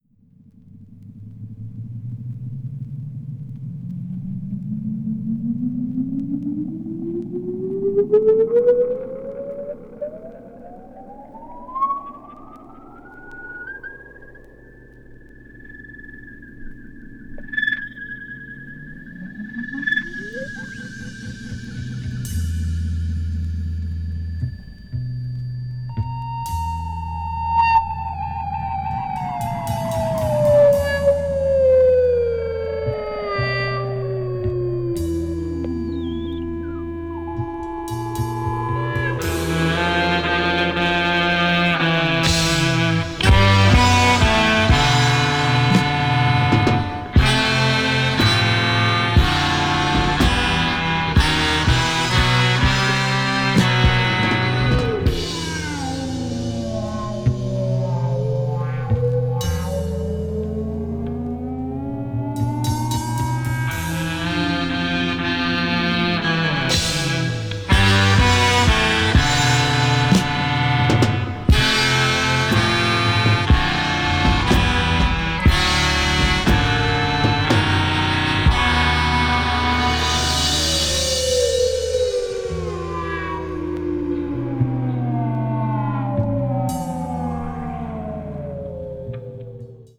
media : EX/EX(わずかにチリノイズが入る箇所あり)
acid folk   avant-garde   experimental   psychedelic rock